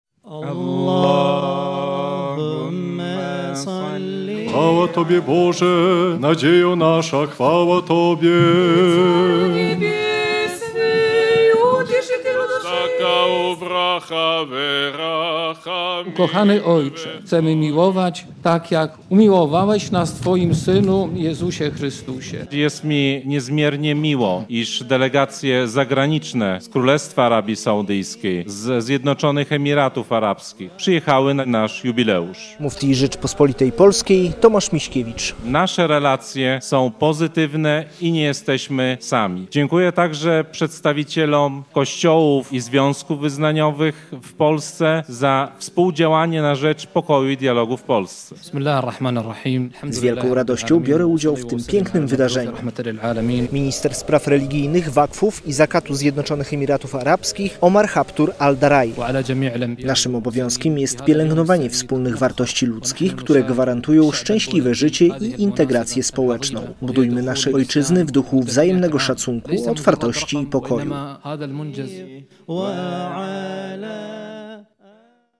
Modlili się o pokój i sprawiedliwość na świecie. Przy meczecie w Bohonikach przedstawiciele i duchowni islamu, chrześcijaństwa i judaizmu w piątek (27.06) wzięli udział w uroczystej, wspólnej modlitwie.